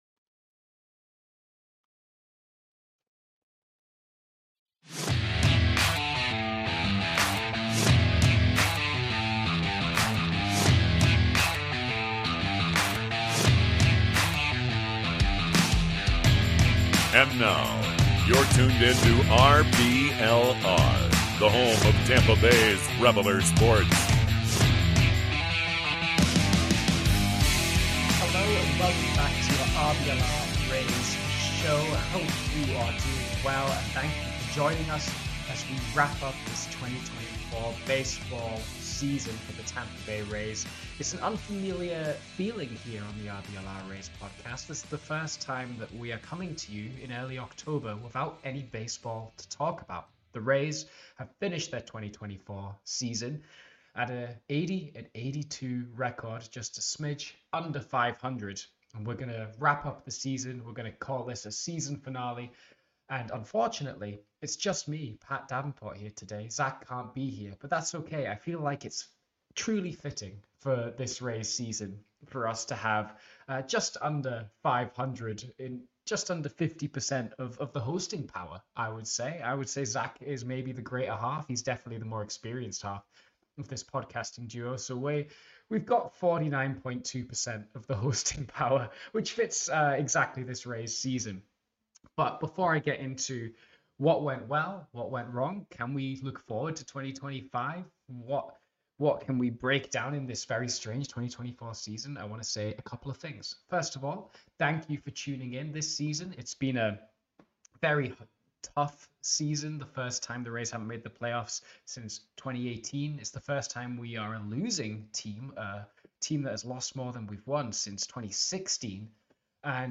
a solo show to give some overall thoughts about the end of the Rays season, how it all went down, what was good – and what are the first things to look forward to next year.